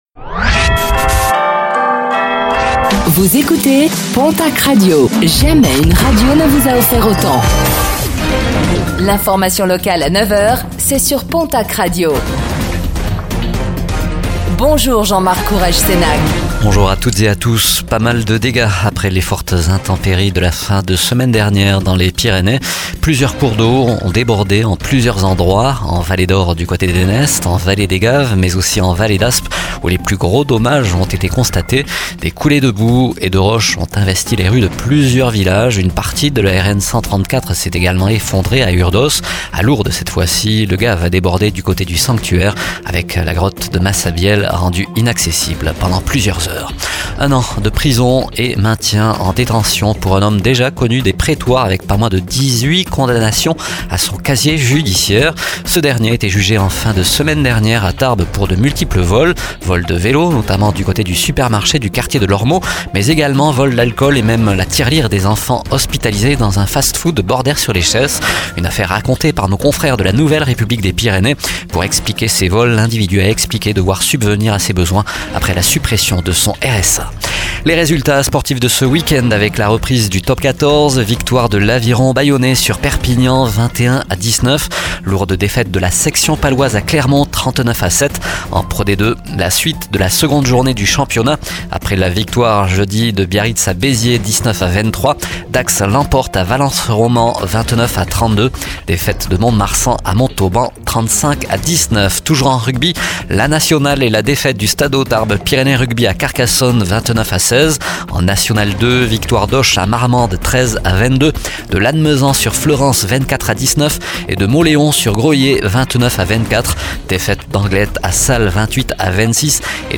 Infos | Lundi 09 septembre 2024